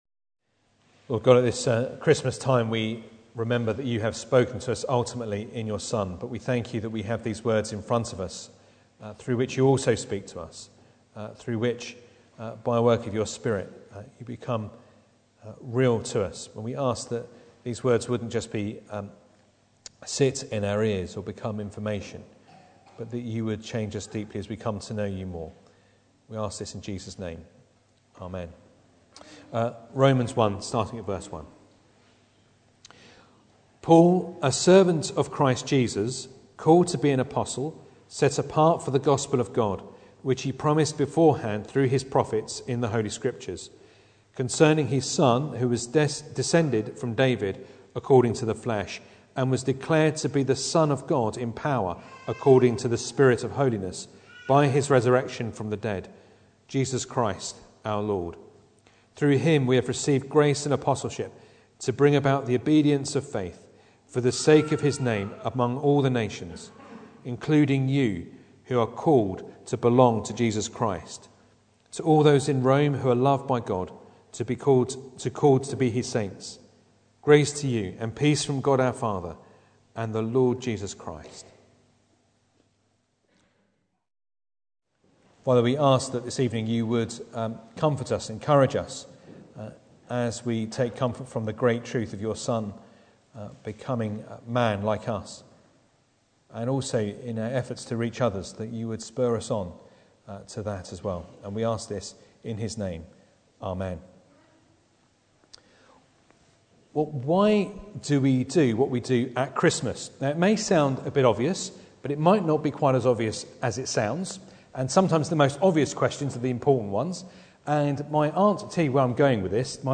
Romans 1:1-7 Service Type: Sunday Evening Bible Text